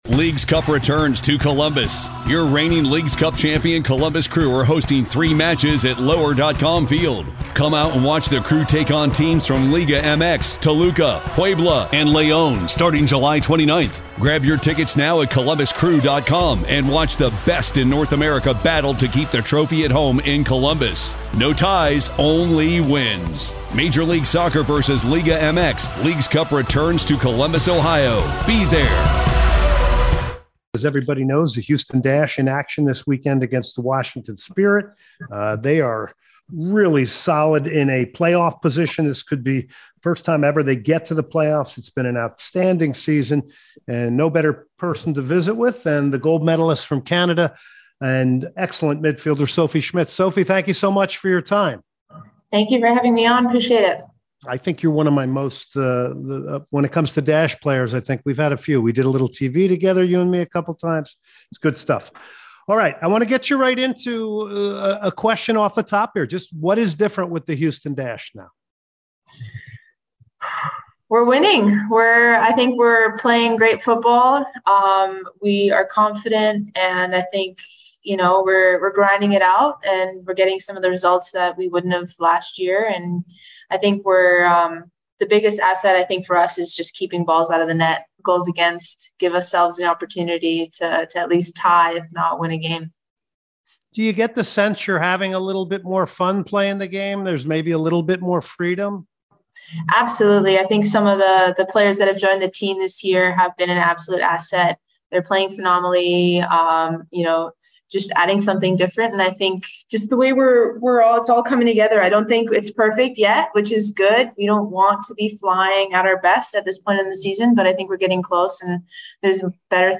starts off the interview asking what her thoughts are on the success of the Dash this season. More over, Sophie goes over her role of a leader ...